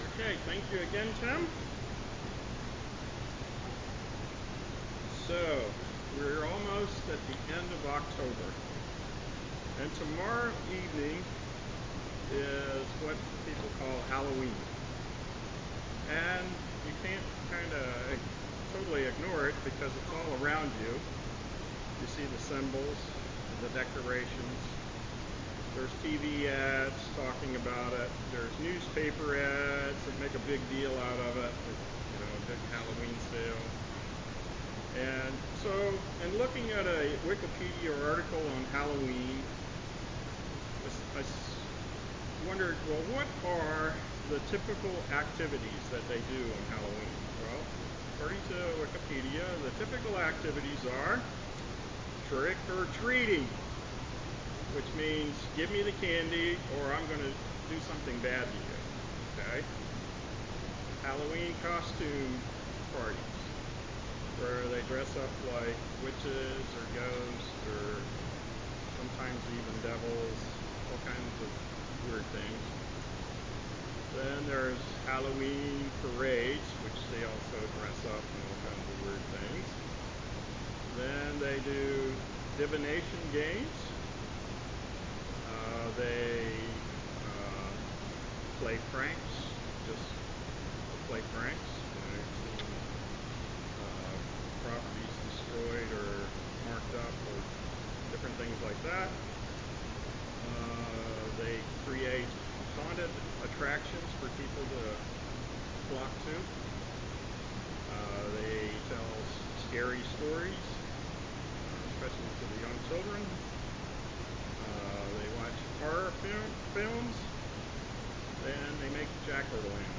This sermon is an overview of what those practices are and what the Bible has to say about them.
Given in Lewistown, PA